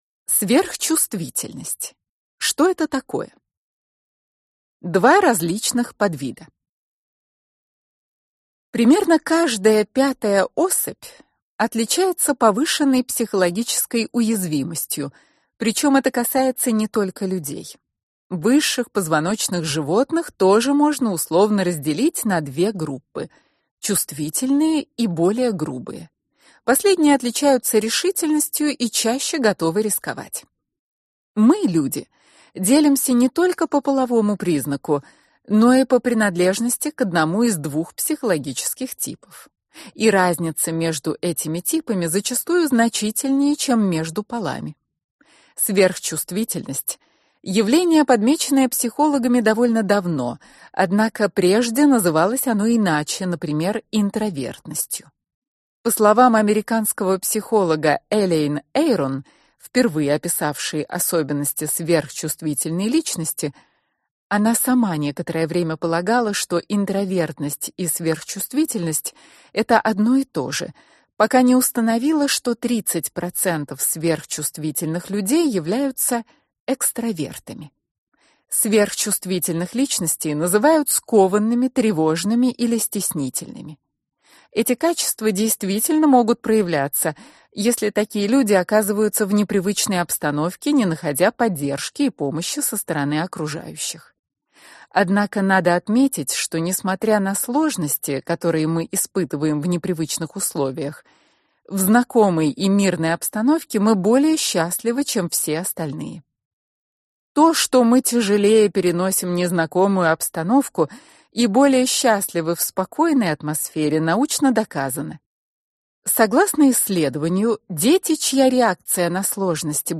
Аудиокнига Близко к сердцу: Как жить, если вы слишком чувствительный человек | Библиотека аудиокниг